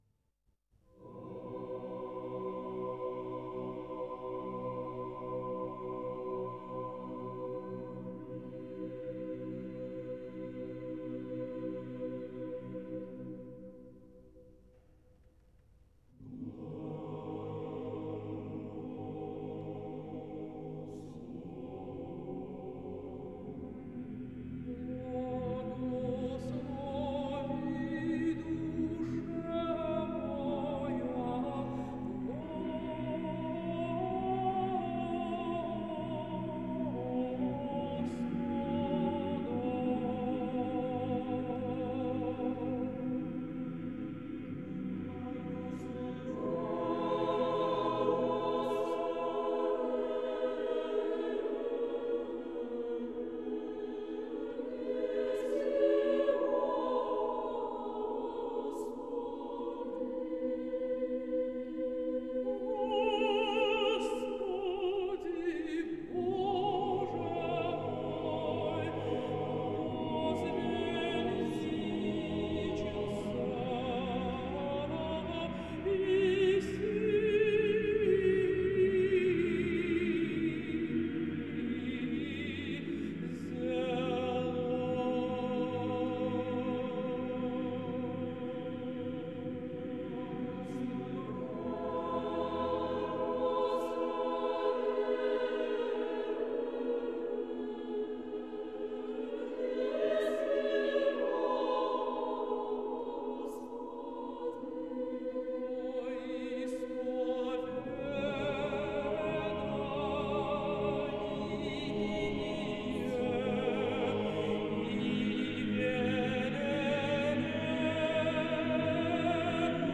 меццо-сопрано